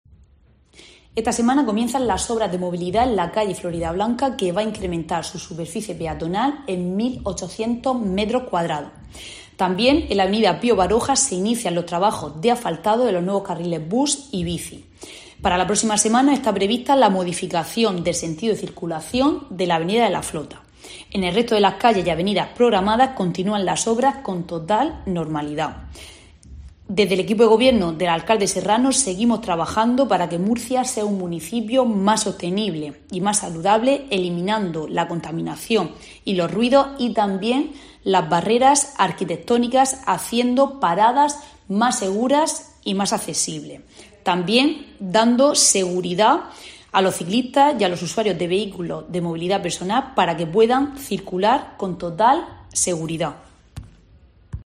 Carmen Fructuoso, concejala de Movilidad